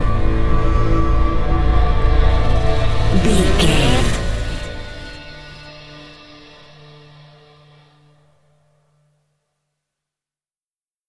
Thriller
Aeolian/Minor
Slow
piano
synthesiser
electric guitar
ominous
dark
suspense
haunting
creepy